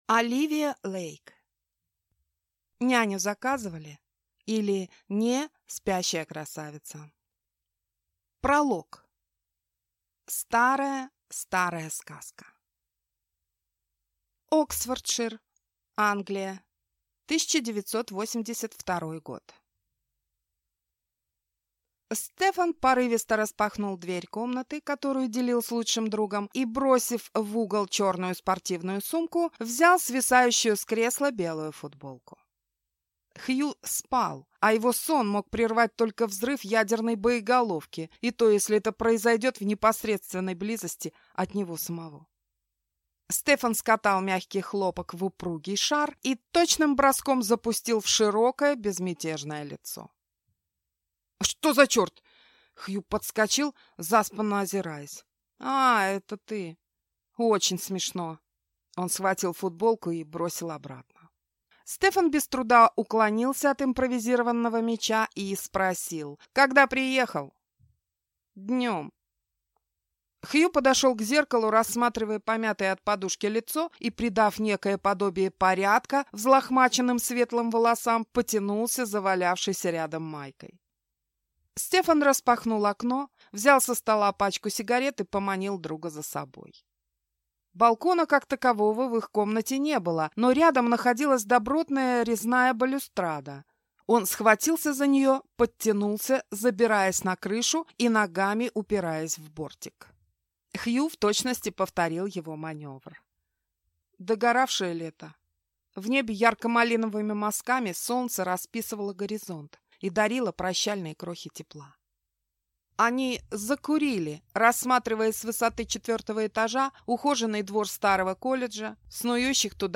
Аудиокнига Няню заказывали? или (не) Спящая красавица | Библиотека аудиокниг